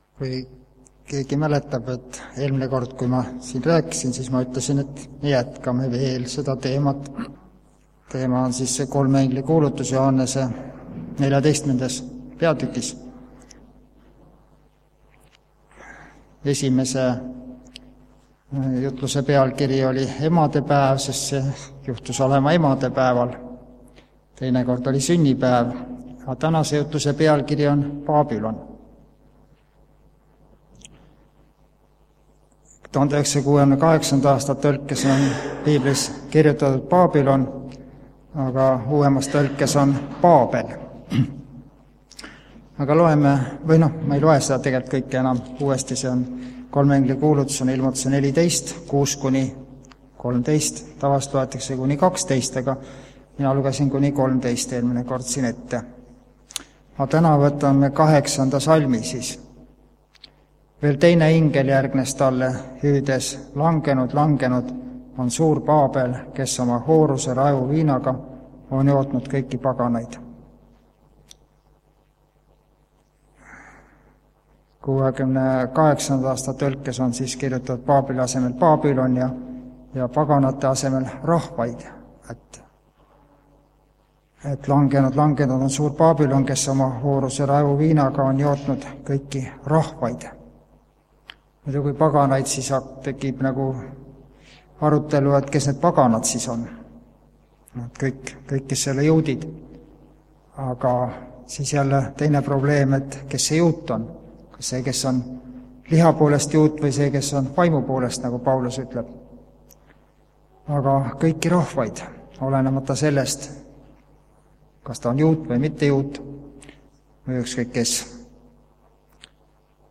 Tänase jutluse nimeks on BAABÜLON sest maailma lõpus on kaks suurt eksitust mida vaimulik baabülon levitab - pühapäeva pühadus ja hinge surematus. Koos laulame ka "Palveränduri laulu"
Jutlused